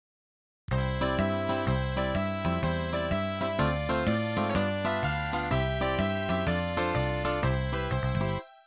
Pop Marsch Gladiale